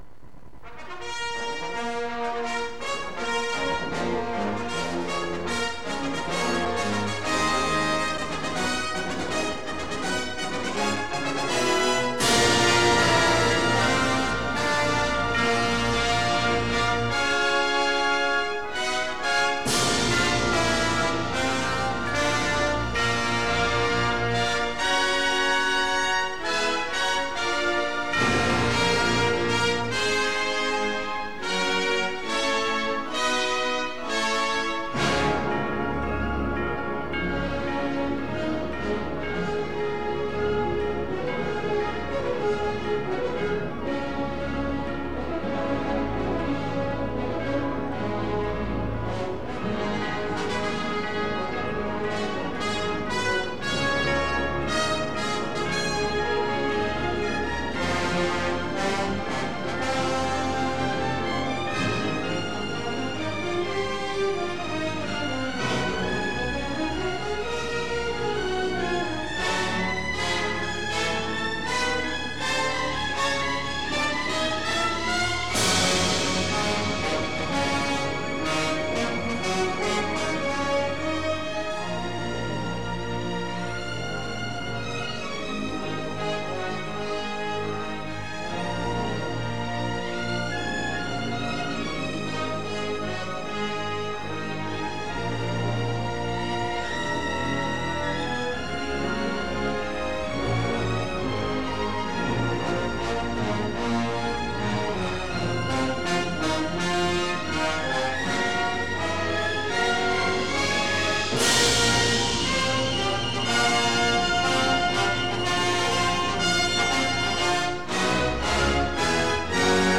Original track music: